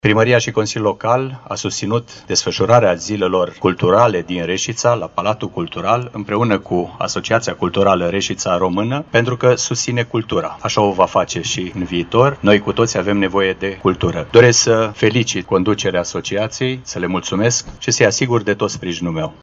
Mihai Stepanescu, primarul municipiului Reşiţa:
Mihai-Stepanescu-primarul-municipiului-Resita.mp3